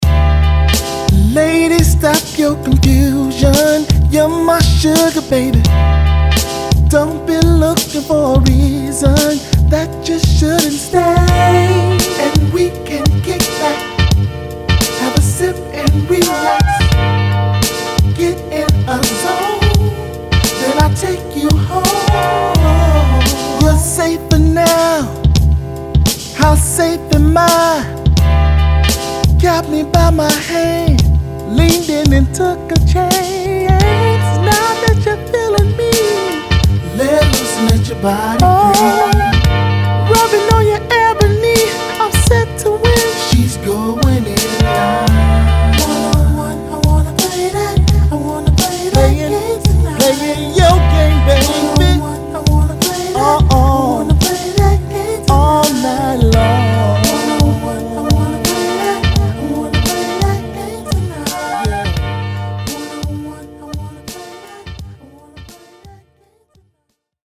Mastering Samples